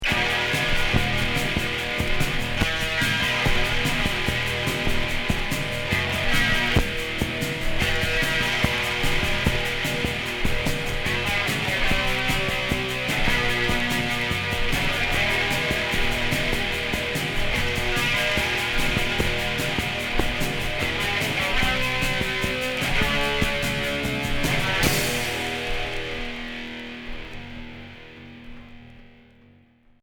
Punk Deuxième 45t